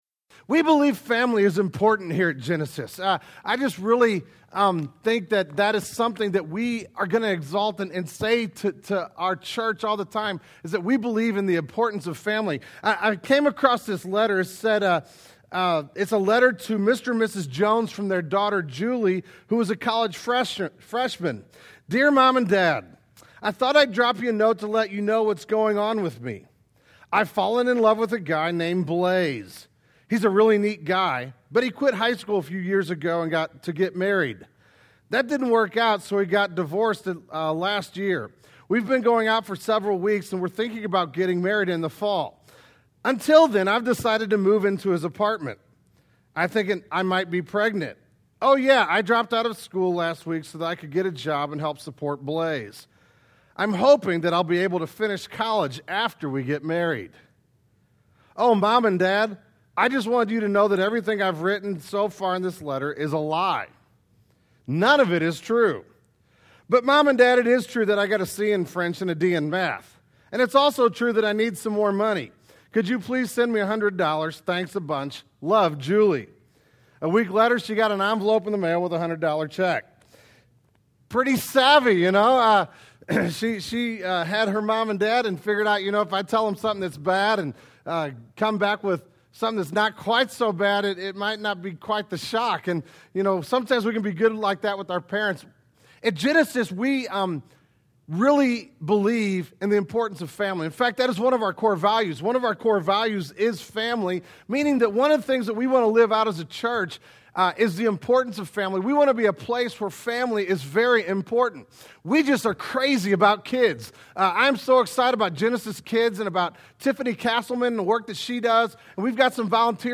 The May 2006 Sermon Audio archive of Genesis Church.